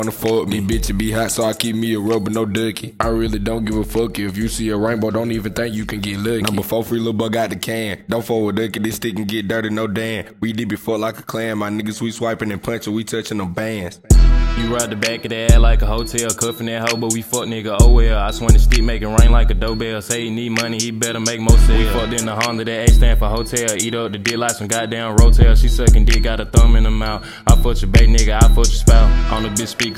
Жанр: Рэп и хип-хоп